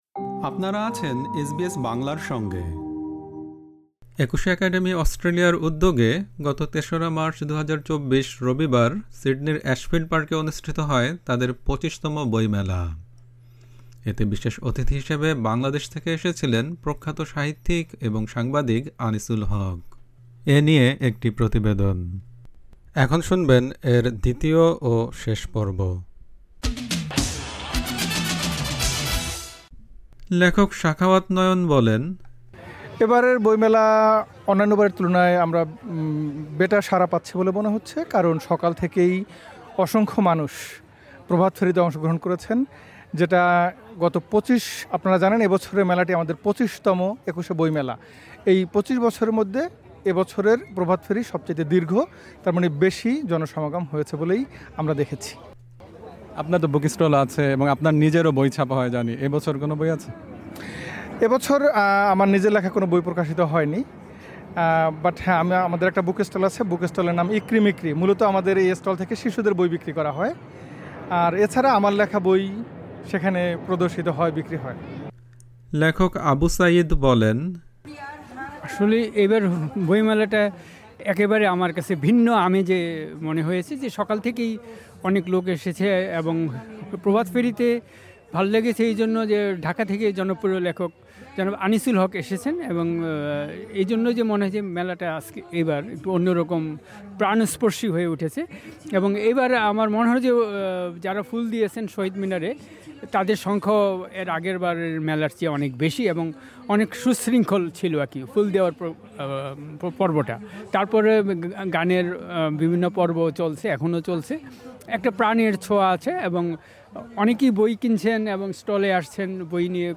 একুশে একাডেমি অস্ট্রেলিয়ার উদ্যোগে গত ৩ মার্চ, ২০২৪, রবিবার সিডনির অ্যাশফিল্ড পার্কে অনুষ্ঠিত হয় তাদের ২৫তম বইমেলা। প্রতিবেদনটির দ্বিতীয় ও শেষ পর্বটি শুনতে উপরের অডিও-প্লেয়ারটিতে ক্লিক করুন।